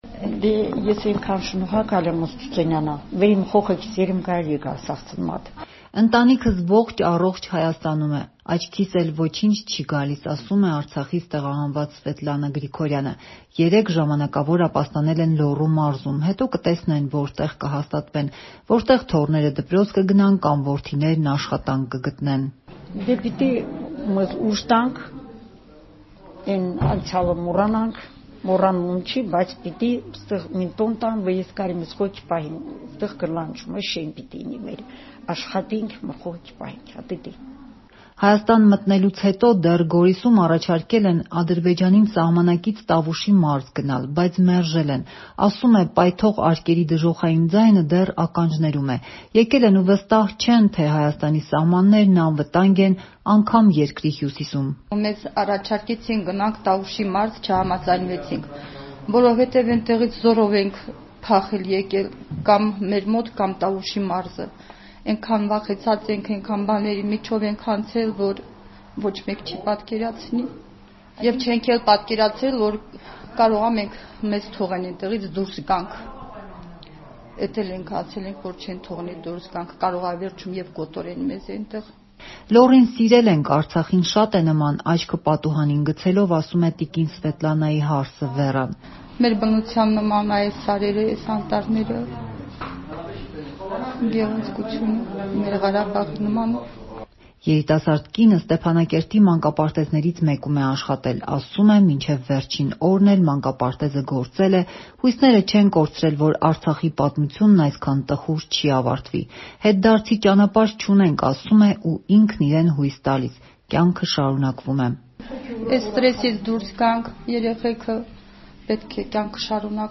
«Չէինք էլ պատկերացրել, որ կարող է մեզ թողնեն դուրս գանք, մտածել ենք, չեն թողնի». արցախցի կին
Ռեպորտաժներ